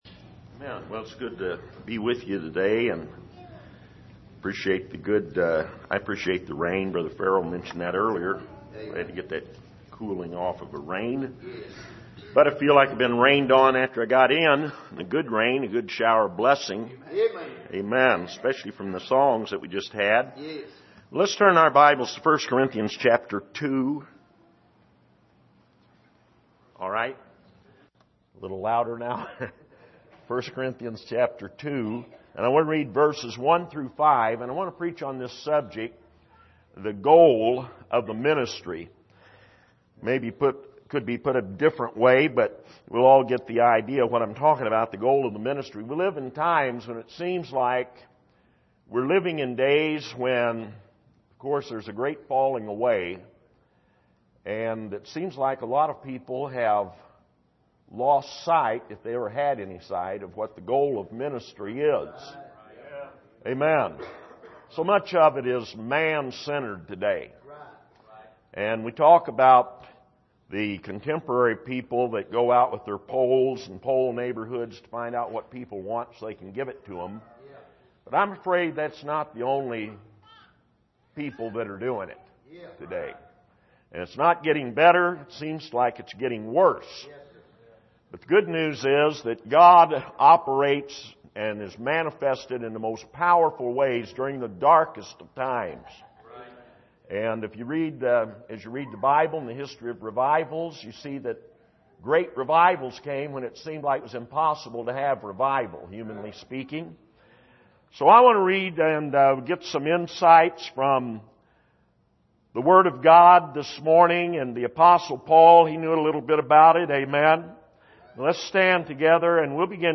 2019 Missions Conference Passage: 1 Corinthians 2:1-5 Service: Missions Conference The Goal of the Ministry « Is There Not A Cause?